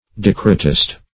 Search Result for " decretist" : The Collaborative International Dictionary of English v.0.48: Decretist \De*cre"tist\, n. [LL. decretista, fr. decretum: cf. F. d['e]cr['e]tiste.